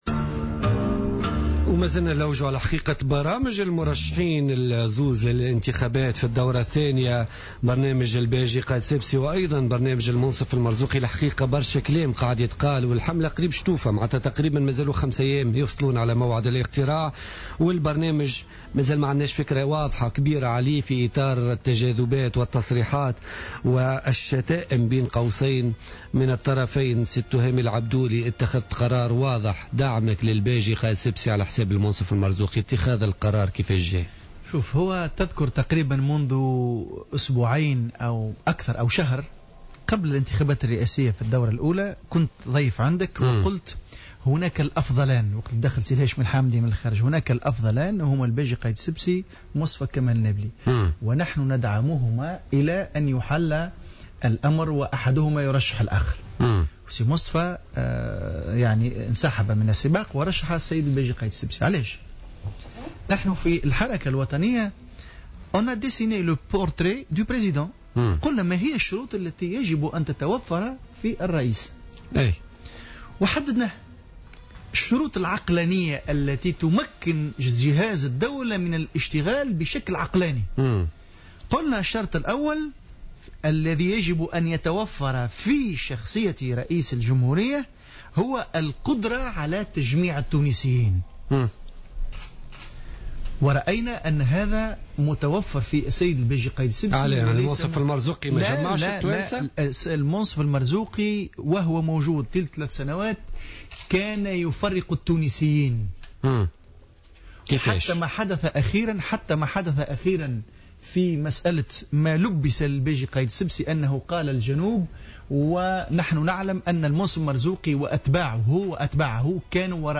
ضيف بوليتيكا